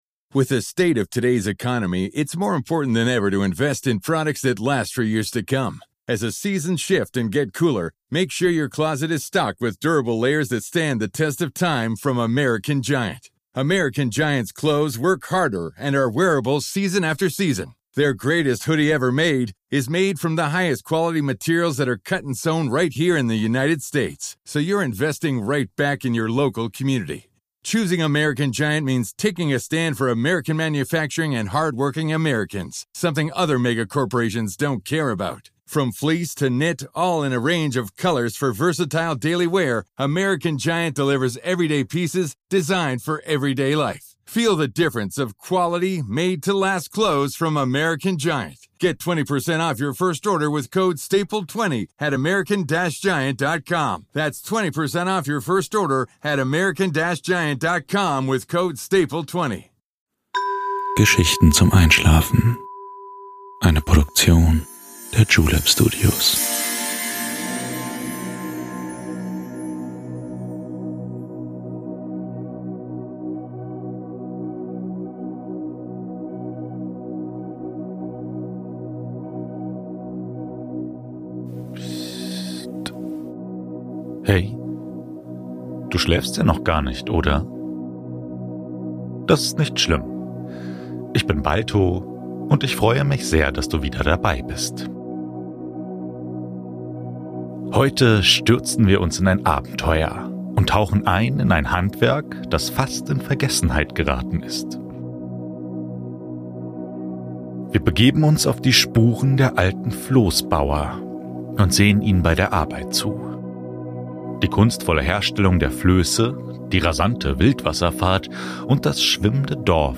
Unsere Geschichten sollen dir dabei helfen, zur Ruhe zu kommen und langsam in einen verdienten und erholsamen Schlaf zu gleiten.